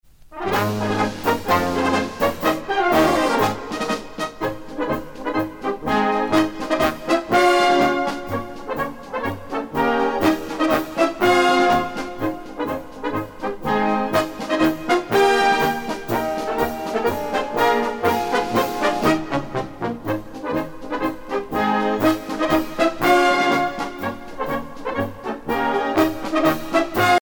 Fonction d'après l'analyste gestuel : à marcher